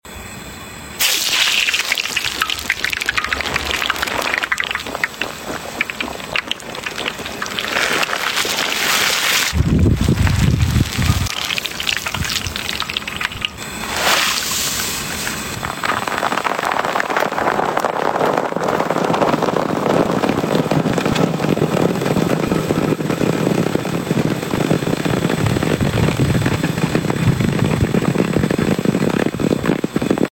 The perfect crackle of popping sound effects free download
The perfect crackle of popping popcorn, with a sweet watermelon twist 🍉🍿✨… a treat for your ears and taste buds!